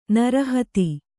♪ nara hati